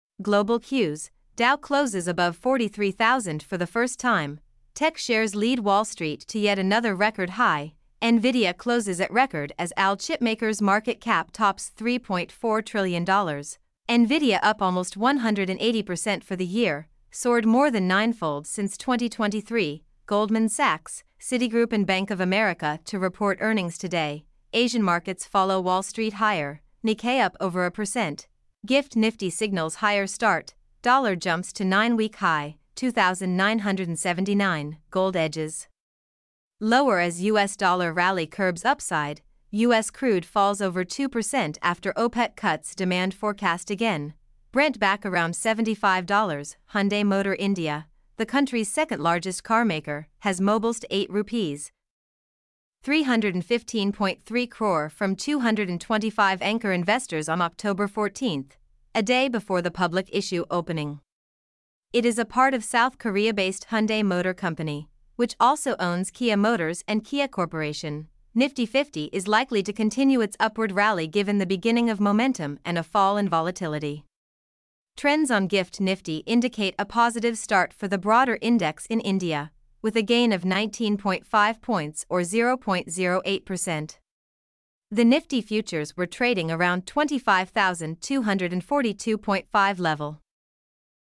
mp3-output-ttsfreedotcom-8.mp3